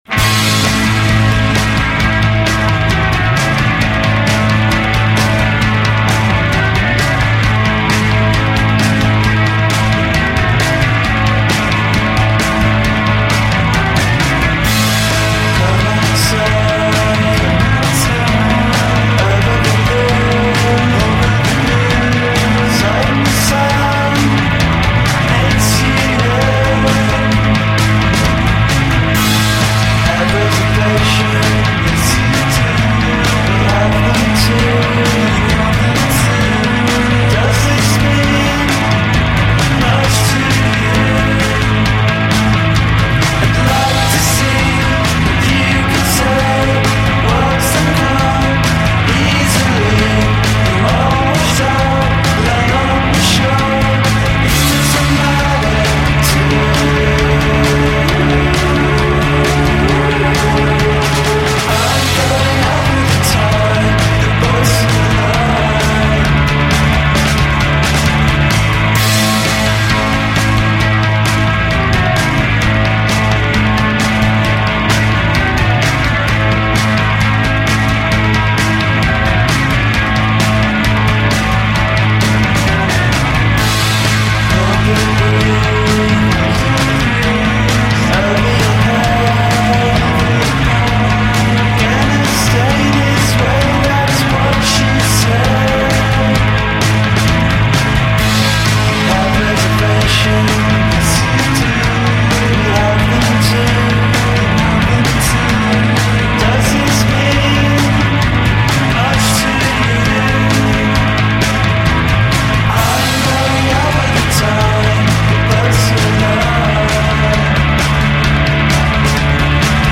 dosa la giusta quantità di melodie ipnotiche